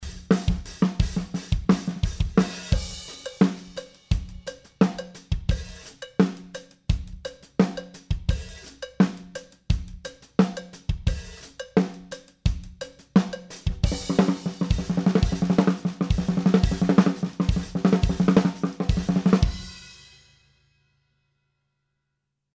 Preamp simulation comparison..
I've been doing a shootout with Uad's newer preamp modeling plugins just using the preamp stage of their plugins set to Mic.
I guess Uad has modeled random differences in saturation/harmonics and how they react level wise in their preamp sections.